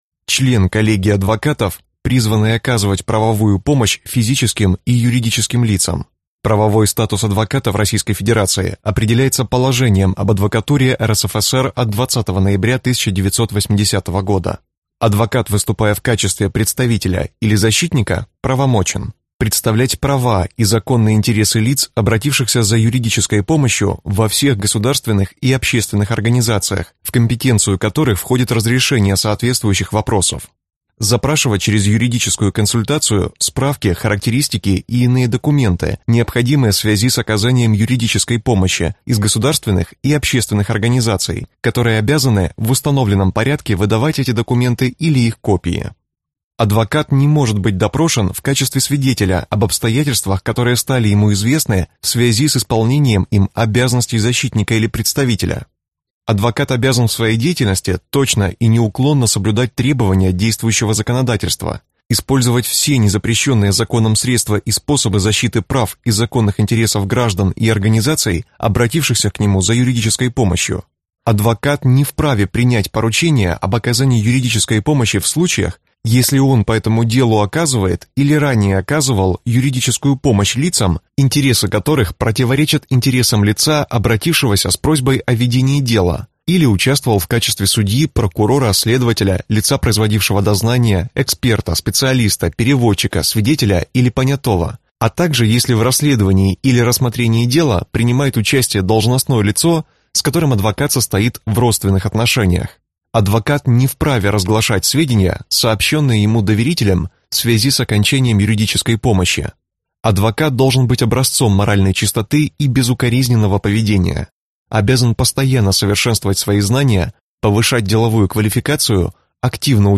Аудиокнига Словарь юриста. Библиотека МВА | Библиотека аудиокниг